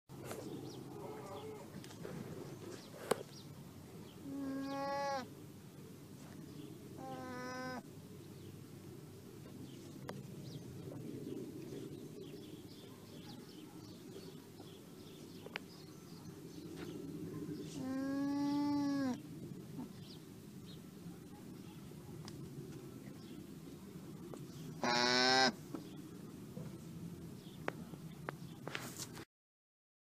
Звук ламы